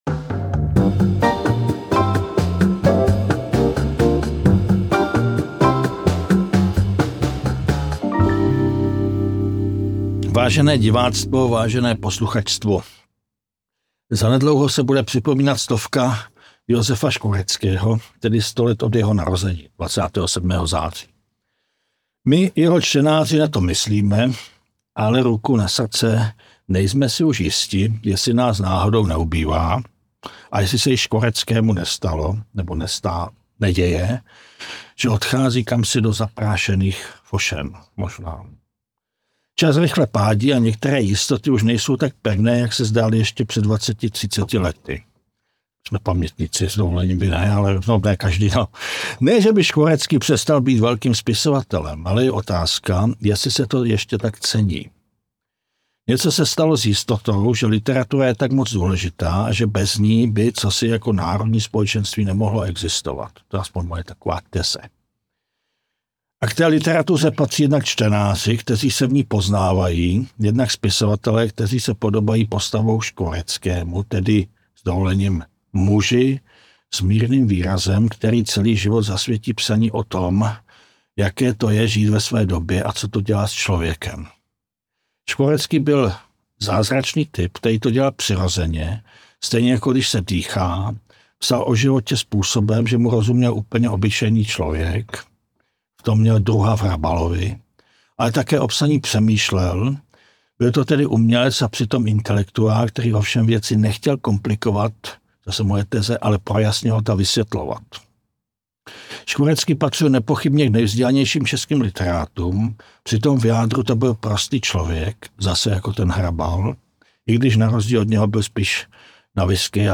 Debata k 100. výročí narození Josefa Škvoreckého Odkaz na RSS feed zkopírován. 0.5x 0.75x 1x 1.25x 1.5x 1.75x 2x 0:00 0:00 Stáhnout MP3 Čas rychle pádí a některé jistoty už nejsou tak pevné, jak se zdály ještě před dvaceti třiceti lety.